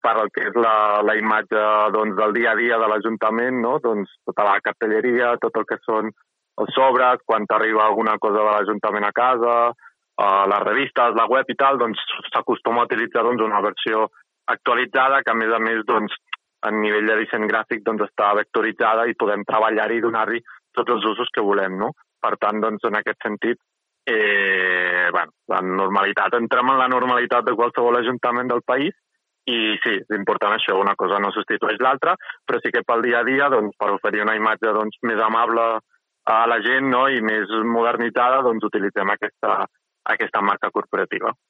Oriol Jordan, regidor de Comunicació de l’Ajuntament de Palafolls, explica que el que es fa demà és el tràmit necessari d’aprovació al ple per impulsar aquesta nova imatge del consistori.